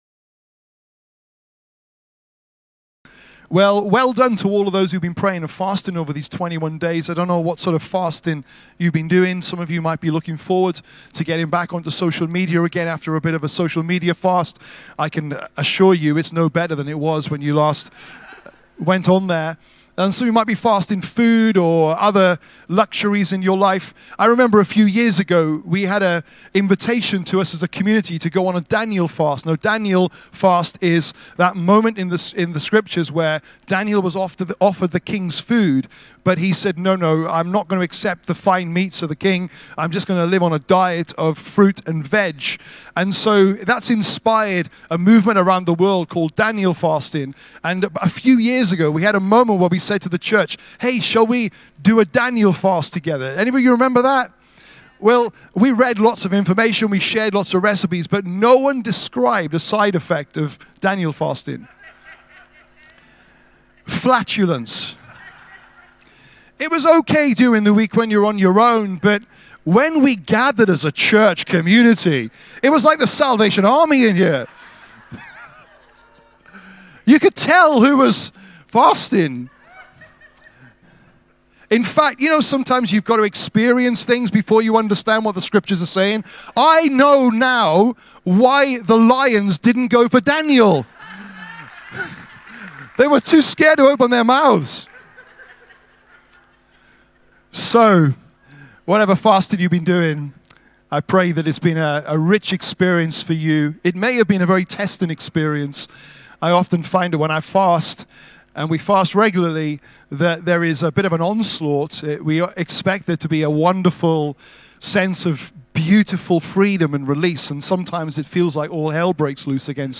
Rediscover Church Exeter | Sunday Messages